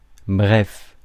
Ääntäminen
Ääntäminen US Haettu sana löytyi näillä lähdekielillä: englanti Käännös Ääninäyte Adverbit 1. brièvement 2. concisément 3. bref {m} France Määritelmät Adverbit (manner) In a brief manner, summarily .